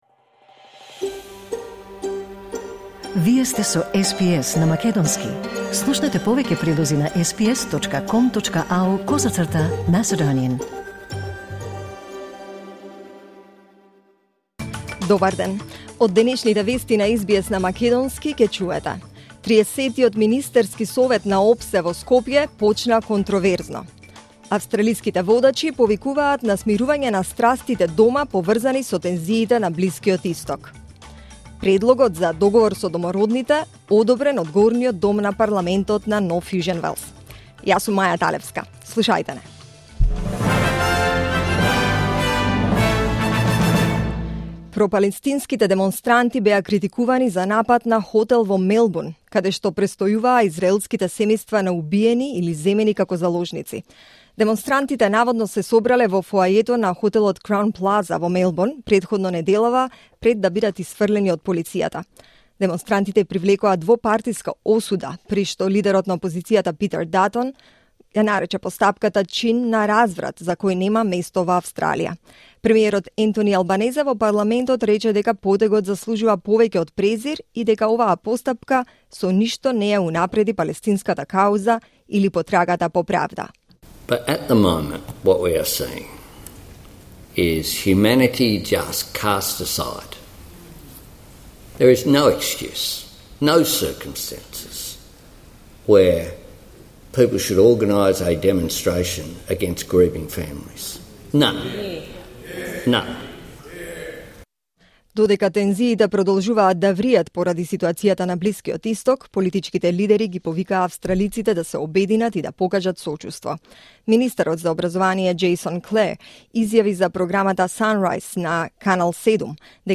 SBS News in Macedonian 1 December 2023